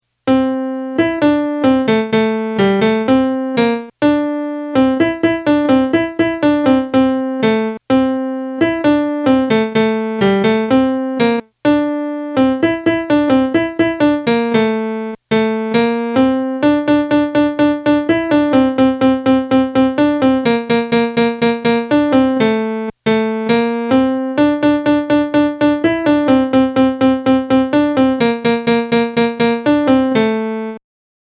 dammi-un-riccio-de-tuoi-capelli-melody.mp3